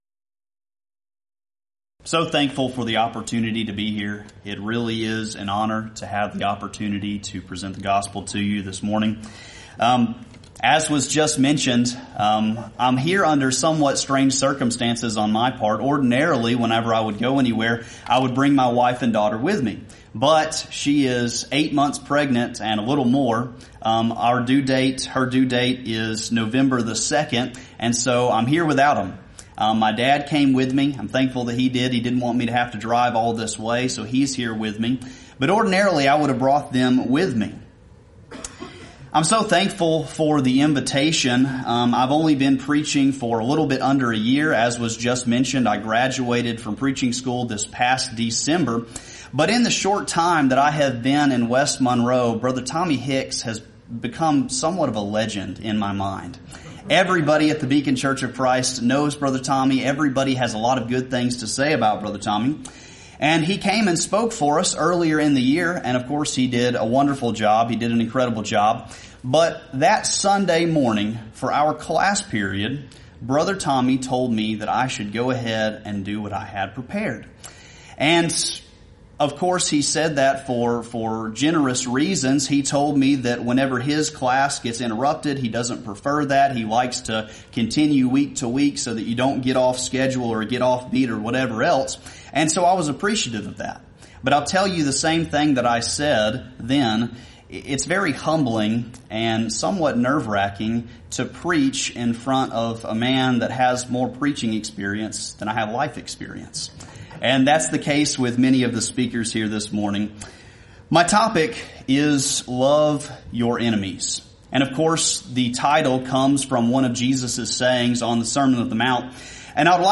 Event: 26th Annual Lubbock Lectures
lecture